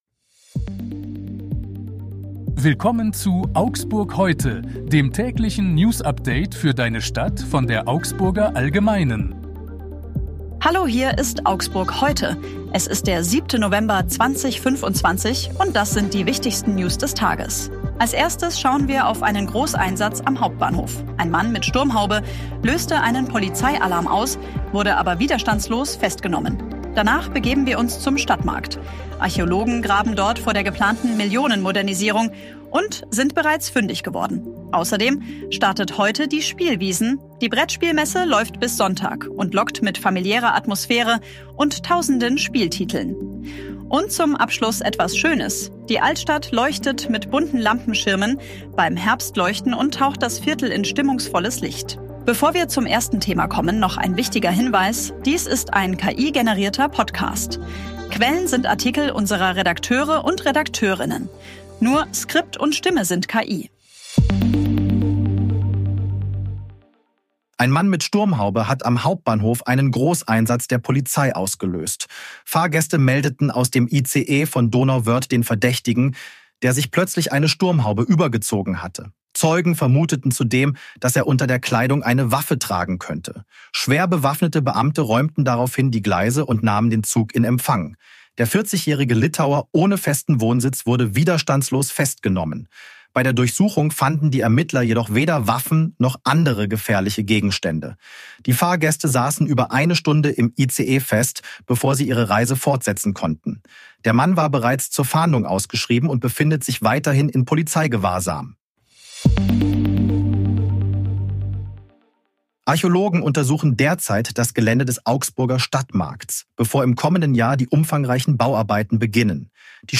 Hier ist das tägliche Newsupdate für deine Stadt.
Redakteurinnen. Nur Skript und Stimme sind KI